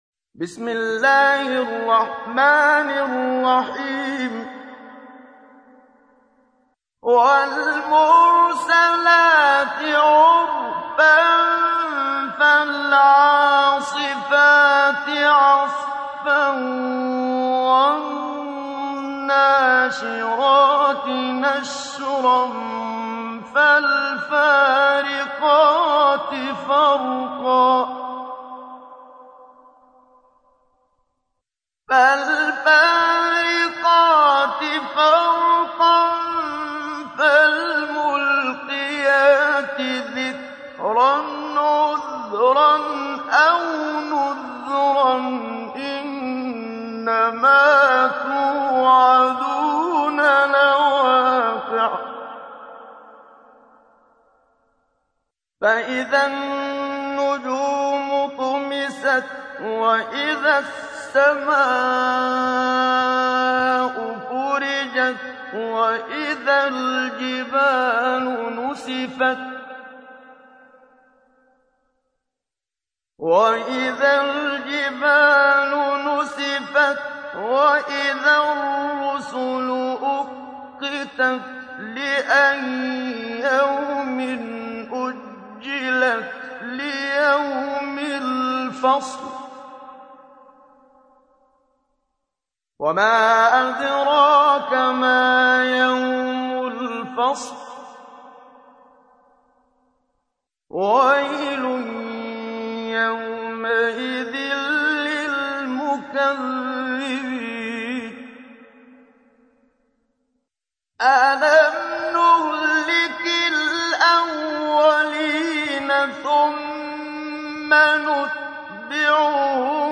تحميل : 77. سورة المرسلات / القارئ محمد صديق المنشاوي / القرآن الكريم / موقع يا حسين